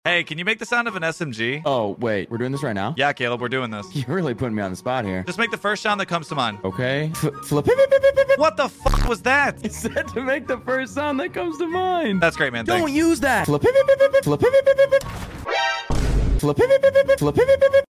New Sounds for SMG’s in sound effects free download
New Sounds for SMG’s in Warzone 2 (Gun Noises)